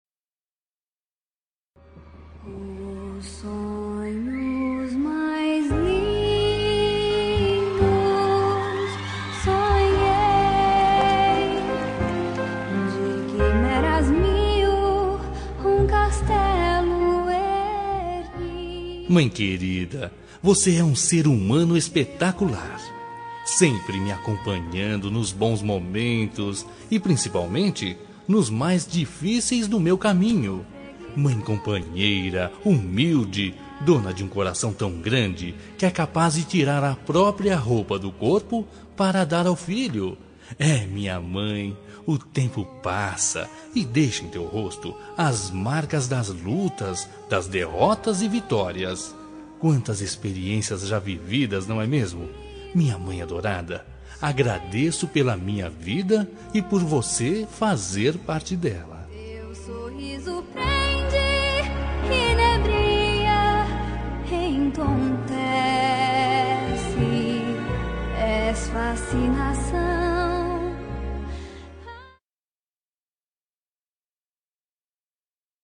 Telemensagem de Agradecimento – Para Mãe – Voz Masculina – Cód: 31
Agradecimento a Mãe -masc- 2027.mp3